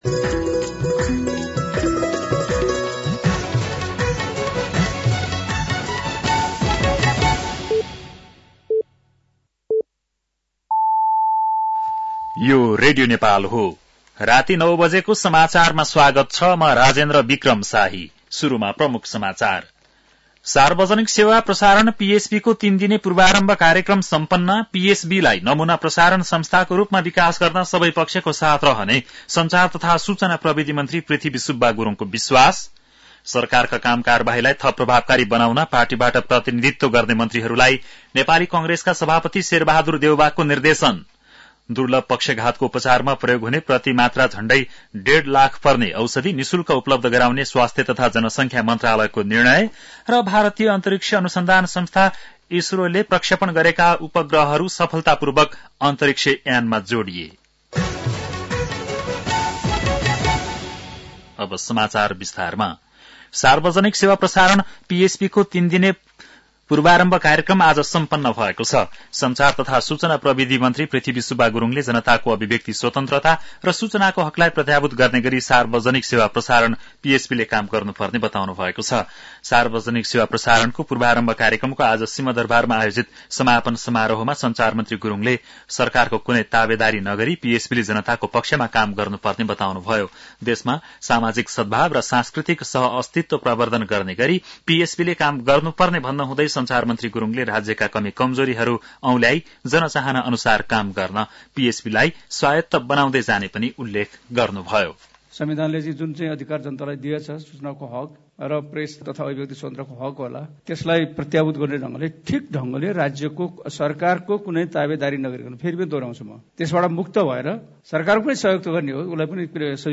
बेलुकी ९ बजेको नेपाली समाचार : ४ माघ , २०८१
9-PM-Nepali-NEWS-10-03.mp3